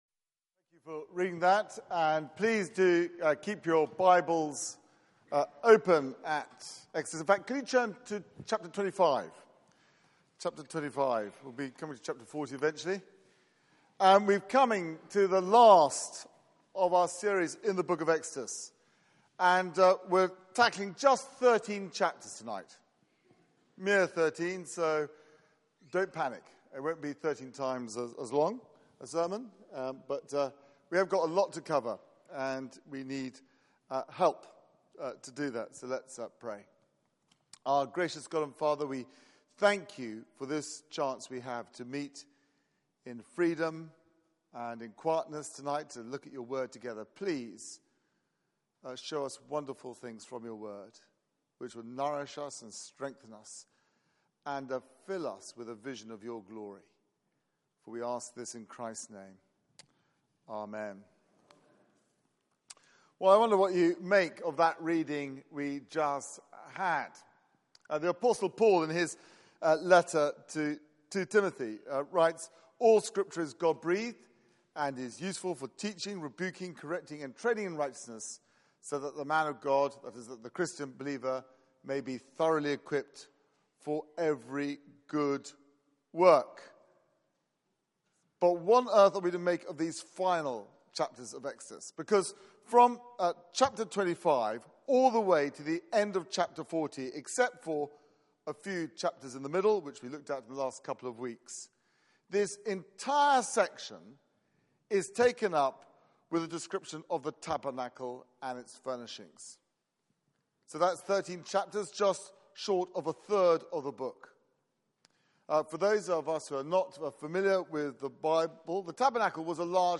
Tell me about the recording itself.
Media for 6:30pm Service on Sun 03rd Jul 2016 18:30 Speaker